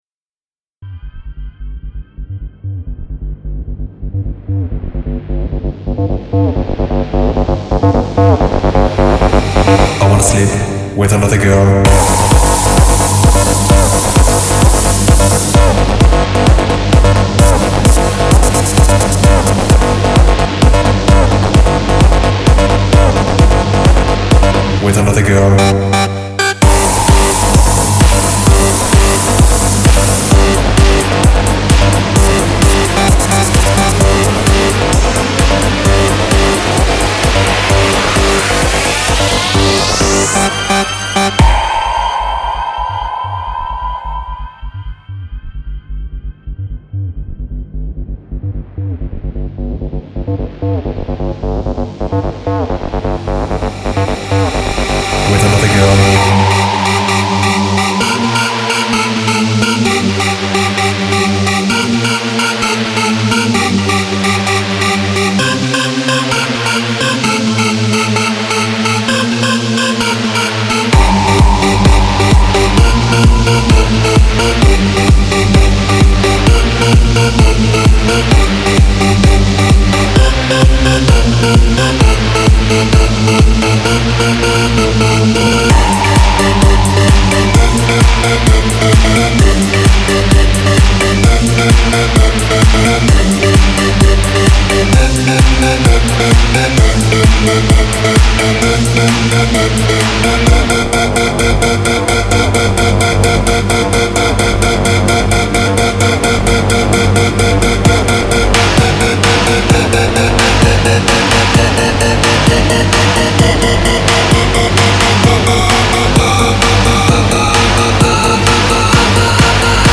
Genre : Electronique